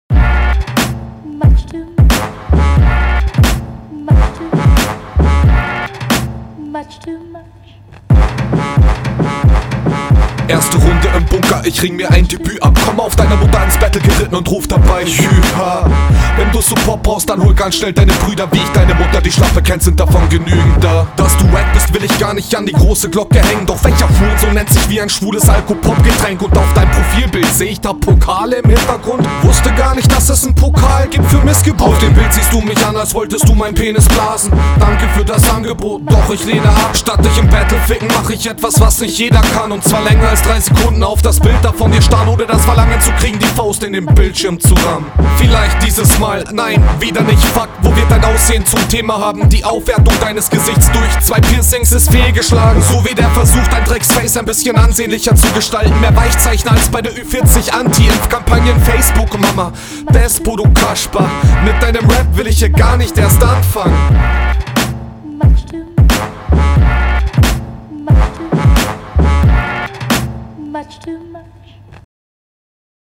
Dein Flow ist sehr stark.
Beat klingt sehr nach Morlockk Dilemma, finde ich gut, für die Bewertung allerdings nicht relevant.
Der Beat klingt mir direkt zu unstrukturiert